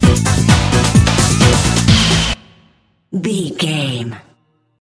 Aeolian/Minor
synthesiser
drum machine
90s
Eurodance